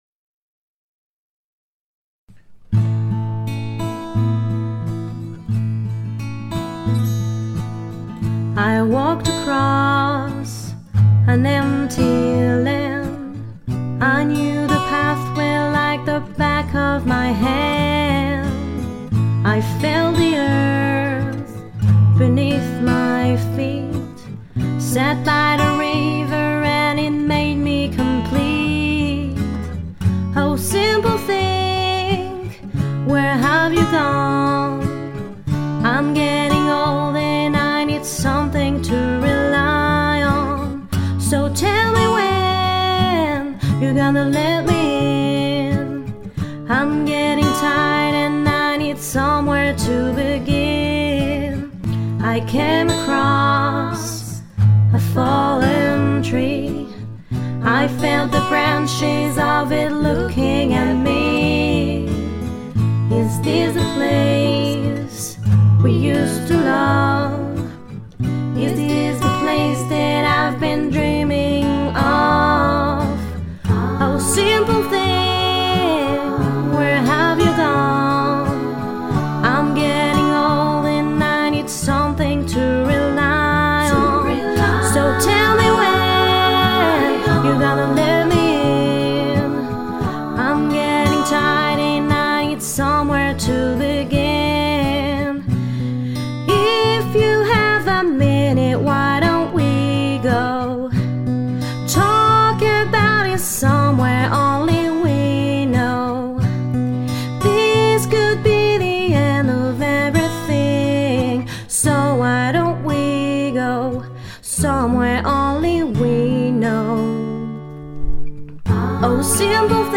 Demo chant anglais
20 - 61 ans - Mezzo-soprano